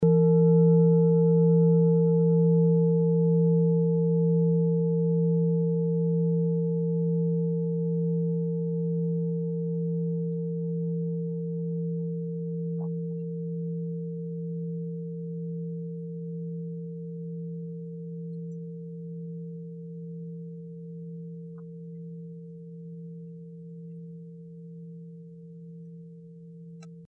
Klangschale Orissa Nr.14
Sie ist neu und wurde gezielt nach altem 7-Metalle-Rezept in Handarbeit gezogen und gehämmert.
(Ermittelt mit dem Filzklöppel)
klangschale-orissa-14.mp3